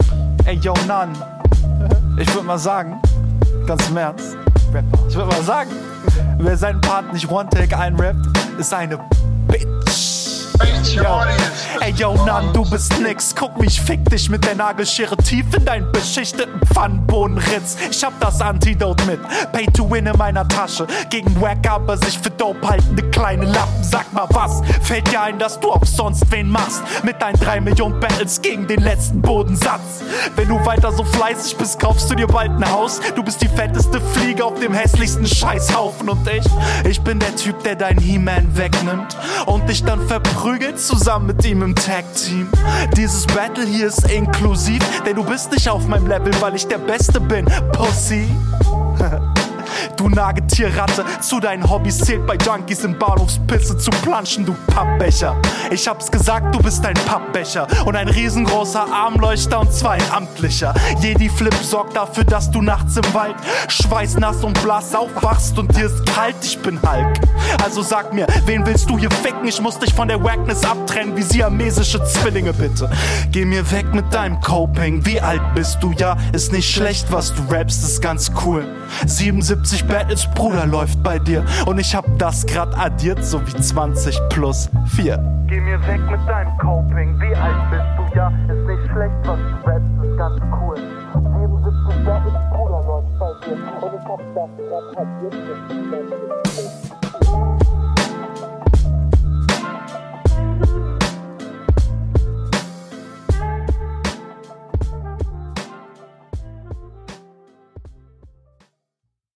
Du hast einen sehr fortgeschrittenen, runden Flow.